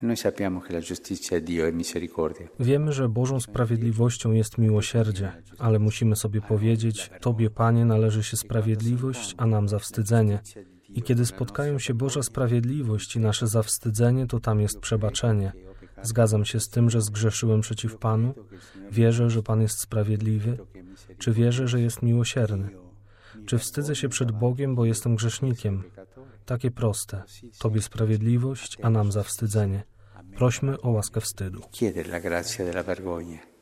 Nie sądźcie, a nie będziecie sądzeni – te słowa Jezusa z Ewangelii św. Łukasza (Łk 6, 36-38) stały się osią przewodnią homilii, którą Papież wygłosił dziś rano na Mszy w Domu św. Marty.